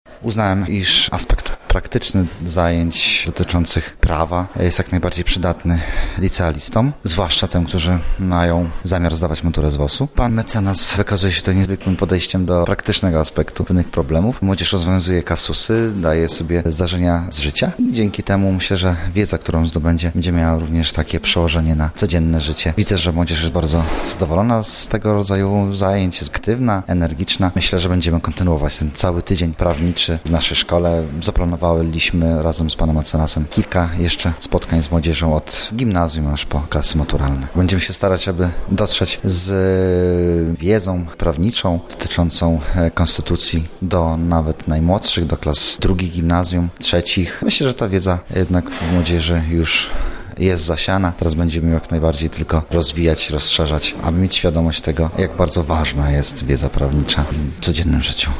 nauczyciel.mp3